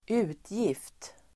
Uttal: [²'u:tjif:t]